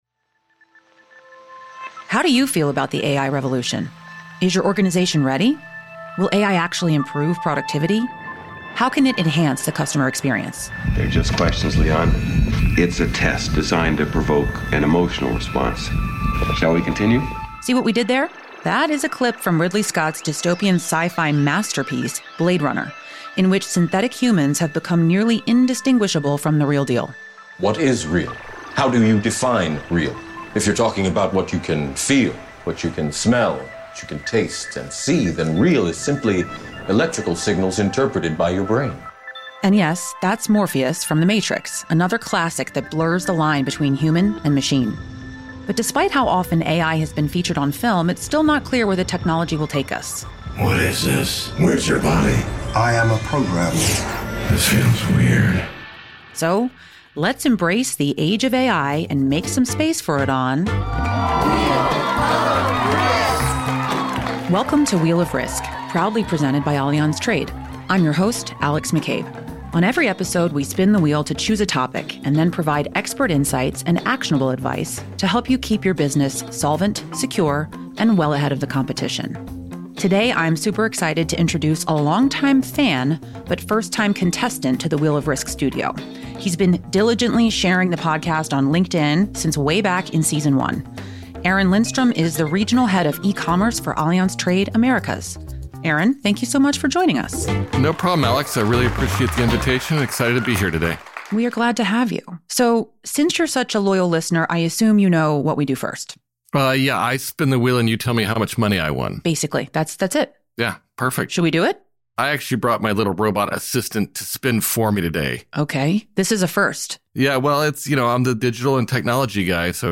test their ability to distinguish AI voices from real ones, in a special quiz at the end of the episode.